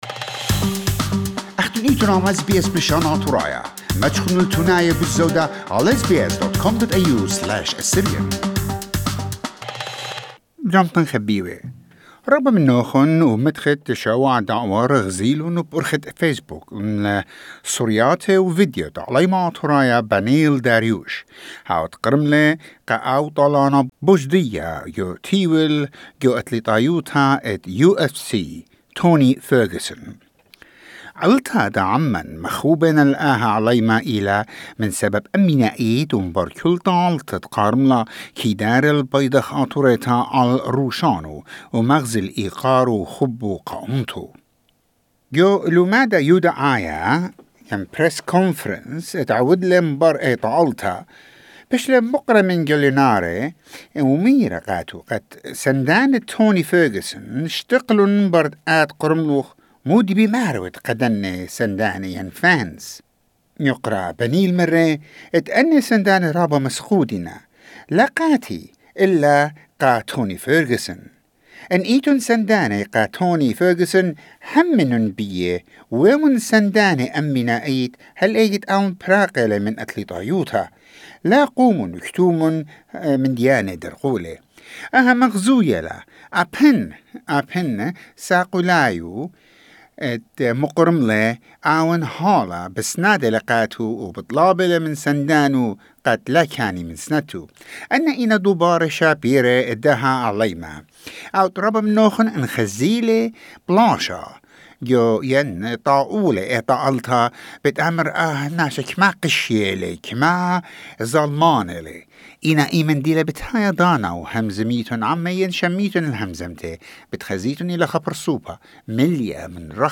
We managed to find this interview in our archives with Beneil after his victory over Carlos Ferreira.
When hearing his soft and gentle voice for the first time, you can't imagine that this voice belongs to such mighty person.